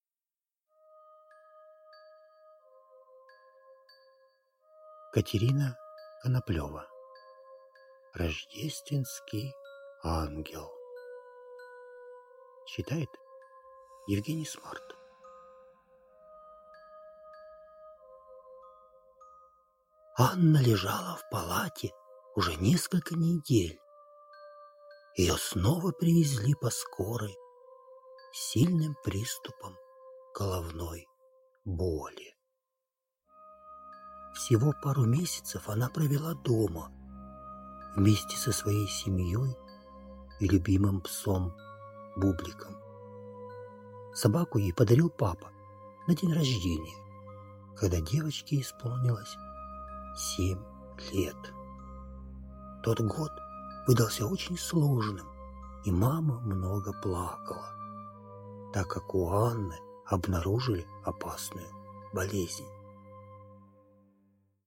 Аудиокнига Рождественский Ангел | Библиотека аудиокниг